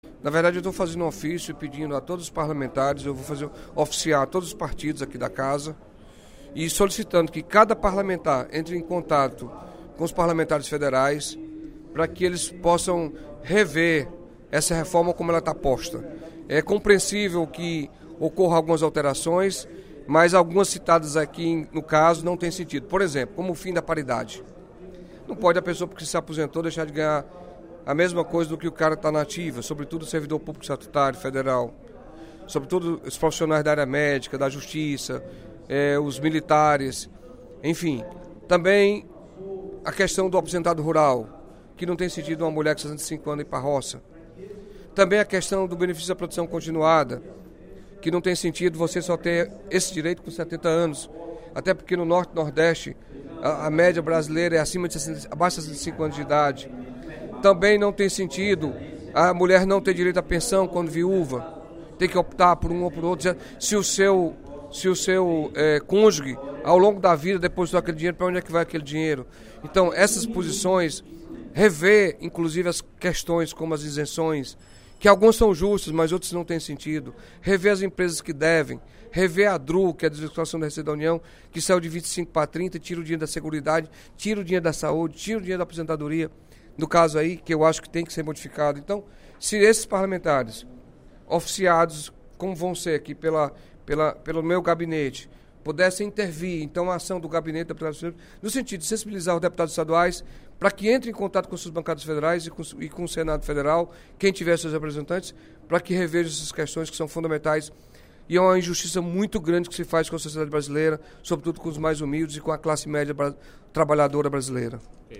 O deputado Carlos Felipe (PCdoB) fez críticas, durante o primeiro expediente da sessão plenária desta quarta-feira (08/03), à proposta de Reforma da Previdência, encaminhada pelo Governo Federal ao Congresso Nacional.